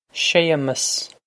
Séamus Shay-muss
This is an approximate phonetic pronunciation of the phrase.